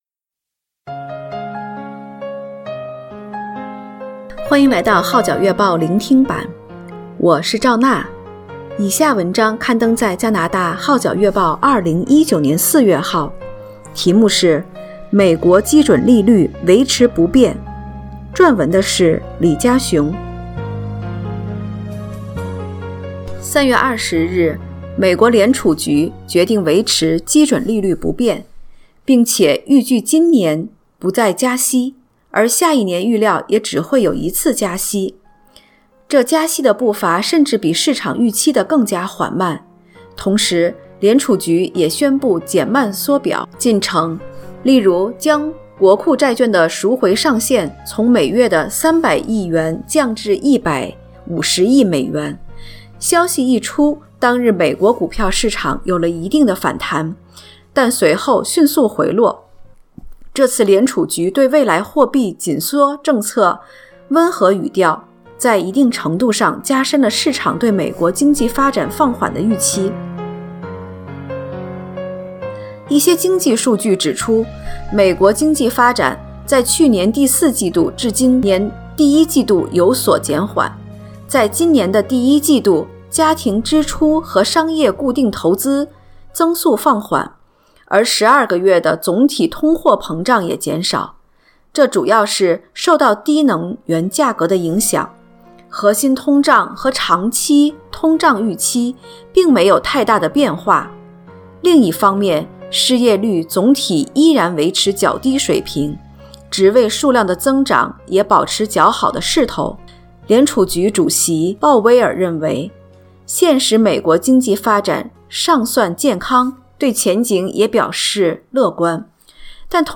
聆聽版/Audio美國基準利率維持不變